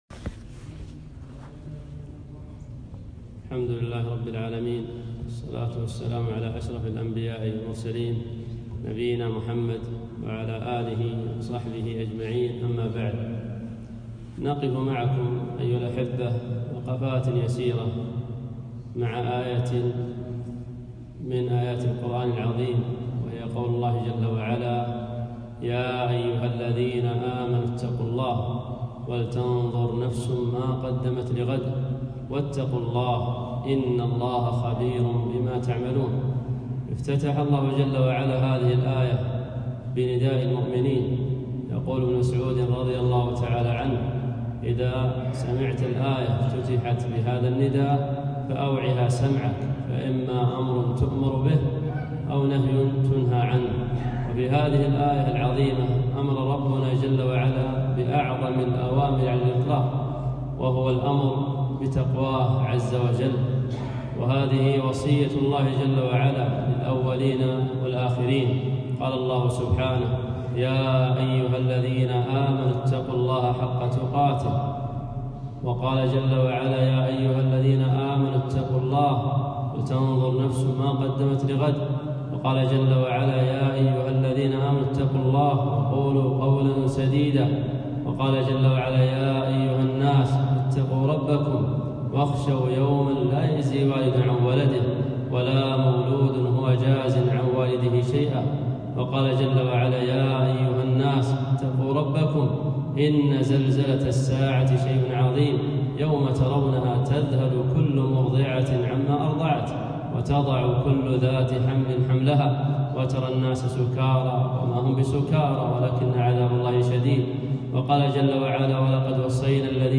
خطبة - أسباب مغفرة الذنوب